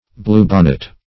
Blue bonnet \Blue" bon`net\ or Blue-bonnet \Blue"-bon`net\, n.